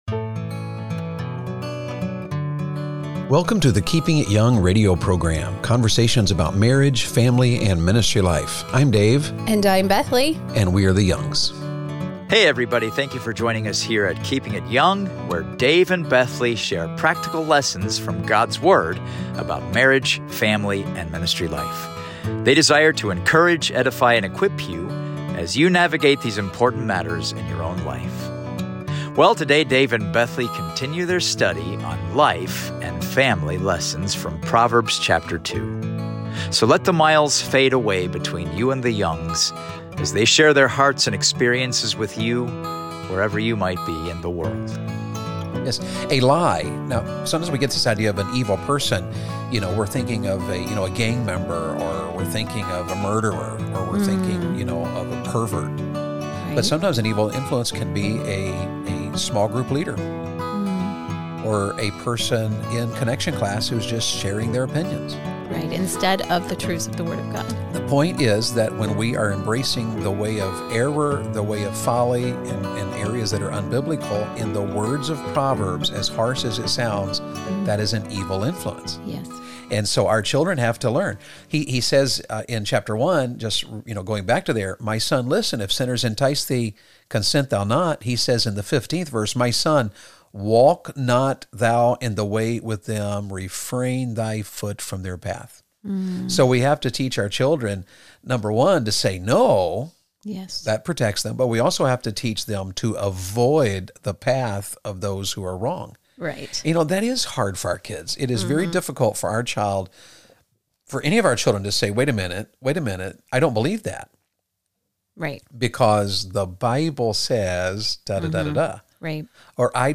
Archived Keeping it Young Radio Broadcast from April 2023